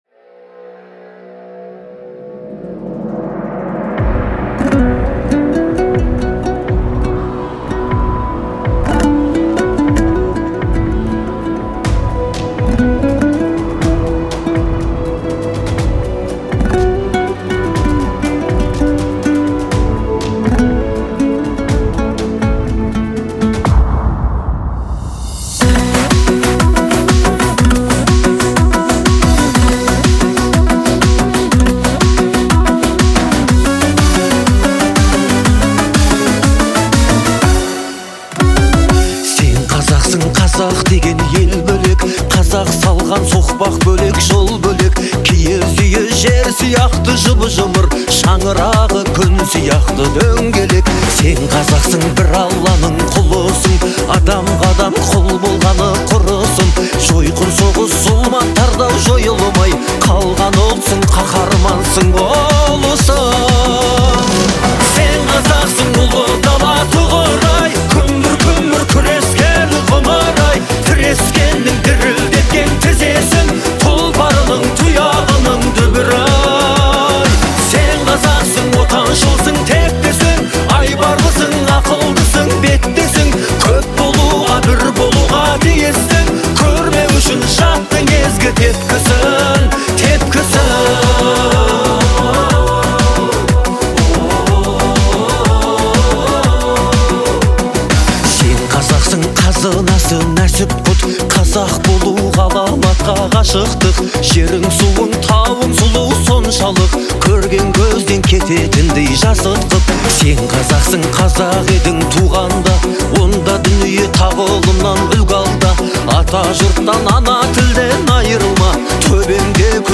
является ярким представителем казахского поп-жанра